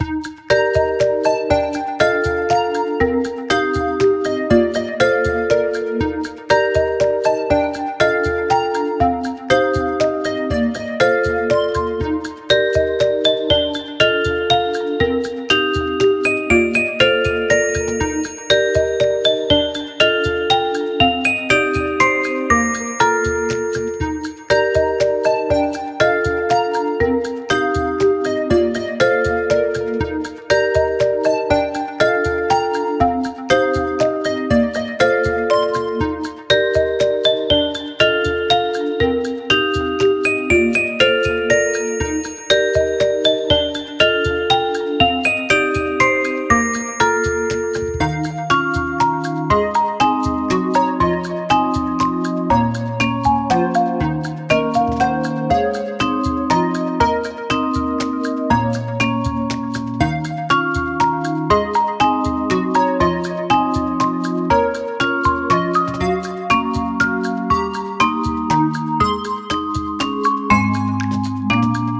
明るい楽曲
【イメージ】かわいい、ほのぼの など